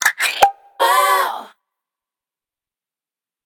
09_Pop.ogg